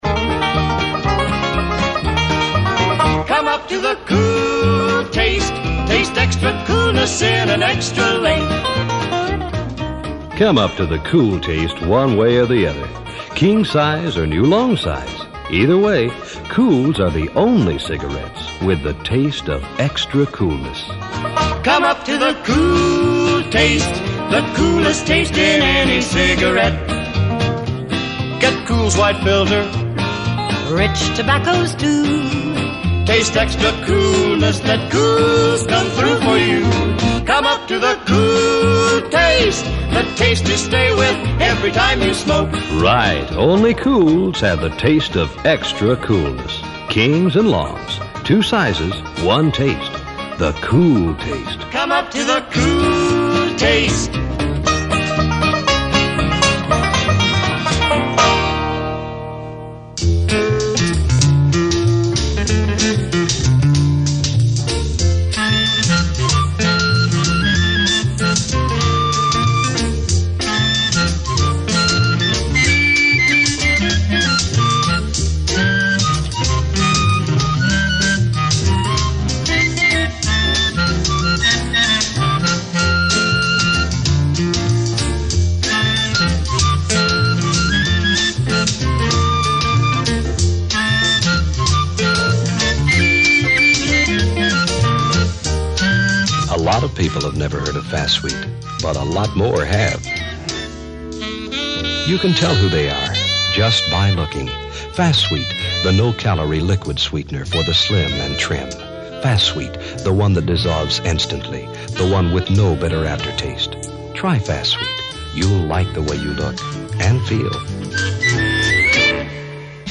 Tape box label for a commercial demonstration tape (circa 1970)